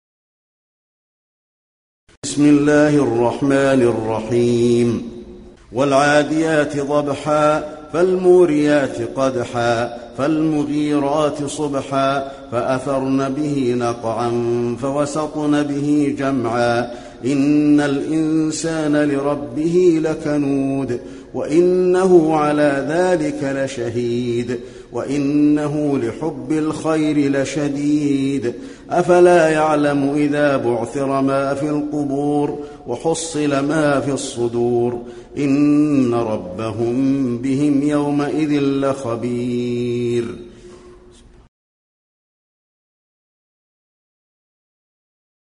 المكان: المسجد النبوي العاديات